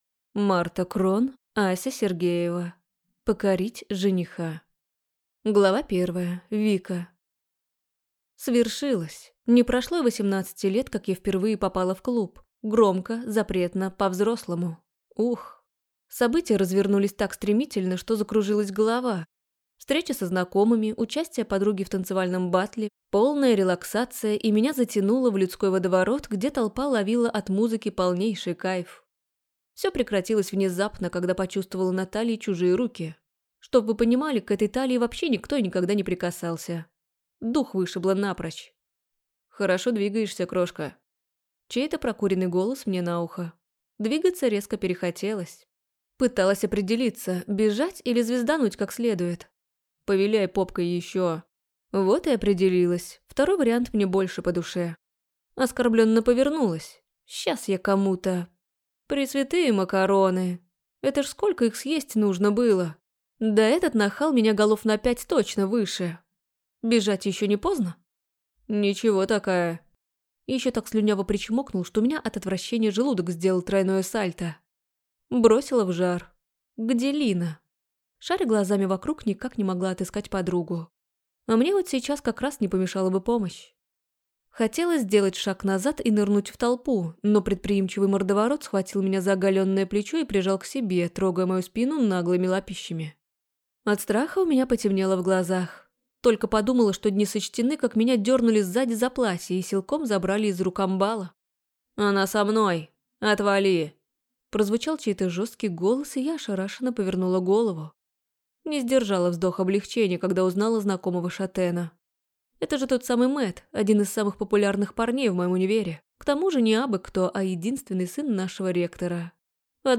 Аудиокнига Покорить жениха | Библиотека аудиокниг